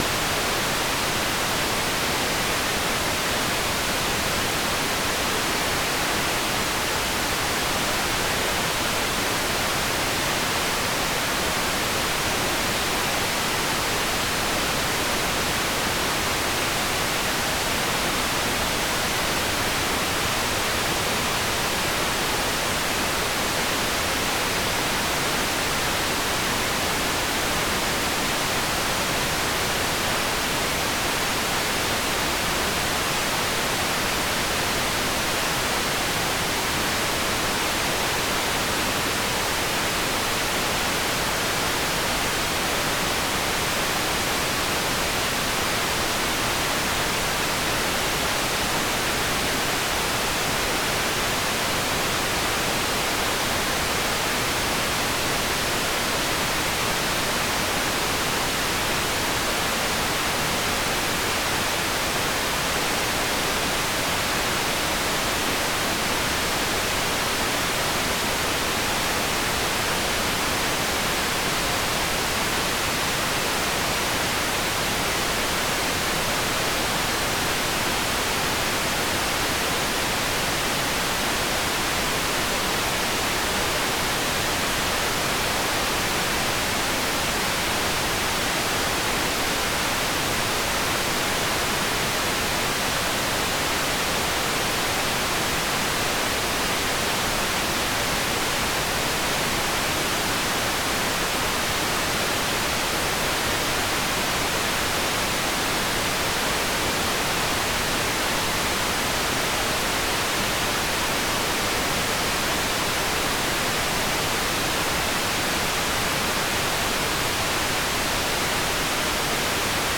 "transmitter_description": "Mode V Imaging",
"transmitter_mode": "SSTV",